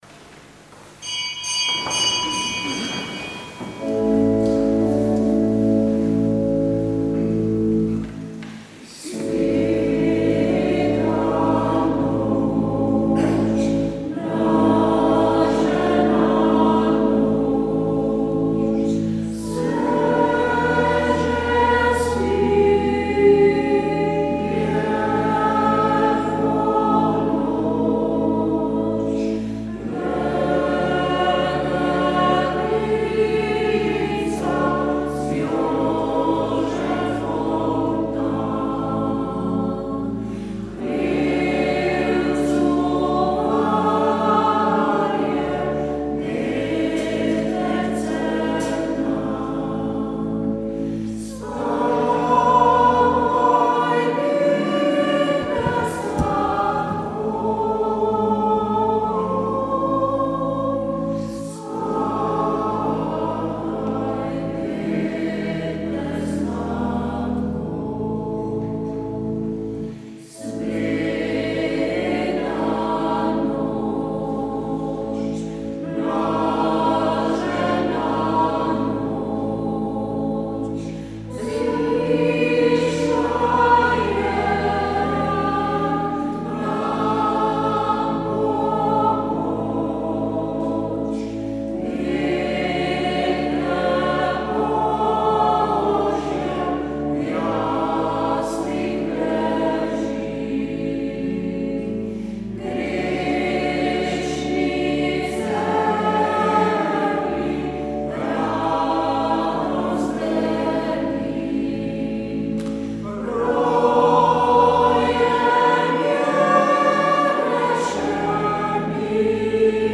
Sveta maša
Sv. maša iz cerkve Marijinega oznanjenja na Tromostovju v Ljubljani 20. 12.